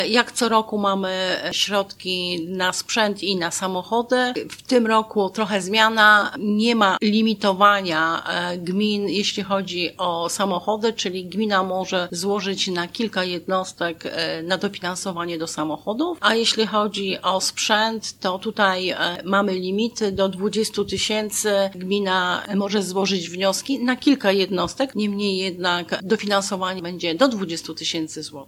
Mówi członek zarządu Janina Ewa Orzełowska: